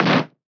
Cartoon Punch Cassette B
2D Animation Cassette Comic Fighting Game Punch SFX sound effect free sound royalty free Movies & TV